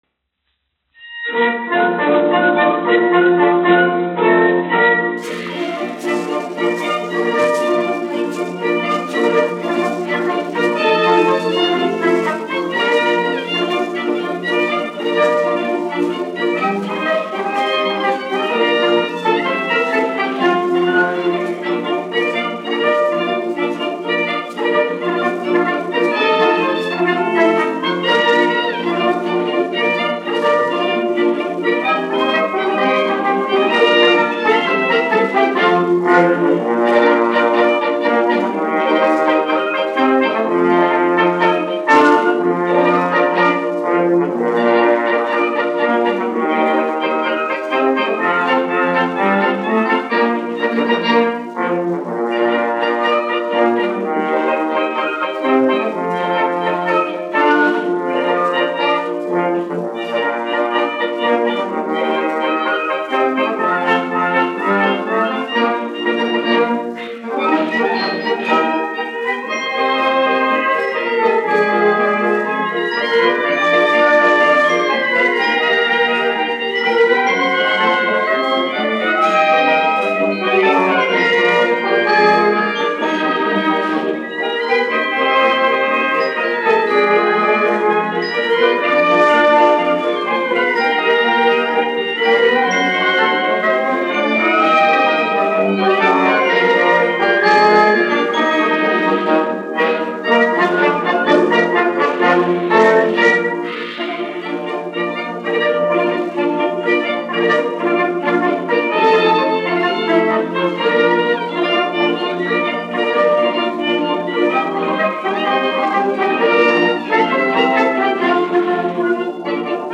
1 skpl. : analogs, 78 apgr/min, mono ; 25 cm
Populārā instrumentālā mūzika--Latvija
Marši
Latvijas vēsturiskie šellaka skaņuplašu ieraksti (Kolekcija)